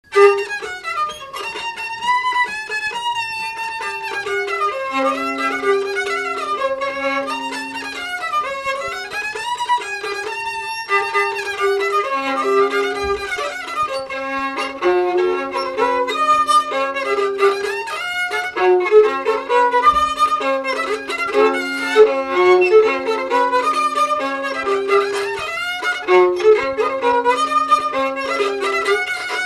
Salazie
Instrumental
danse : séga
Pièce musicale inédite